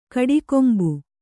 ♪ kaḍikombu